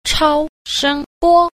6. 超聲波 – chāoshēngbō – siêu thanh ba (sóng siêu âm)